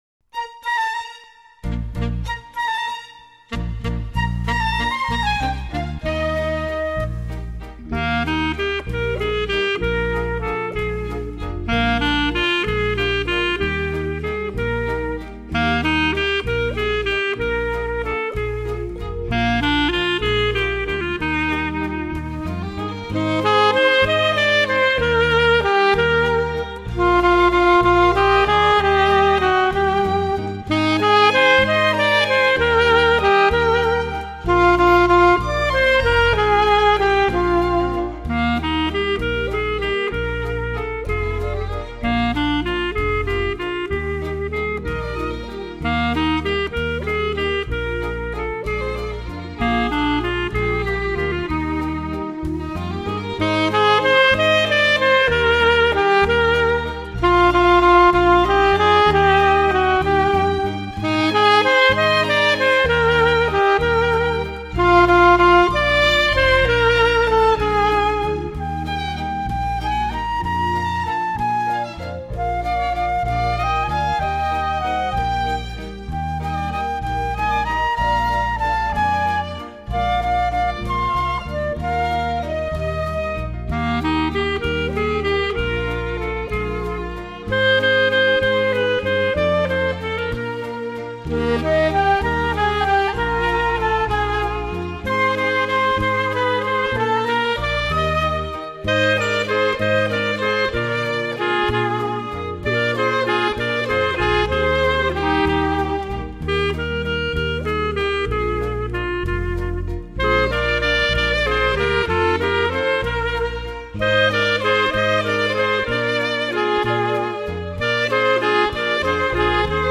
ボーカル
アルトサックス
クラリネット＆フルート
バイオリン
ベース
キーボード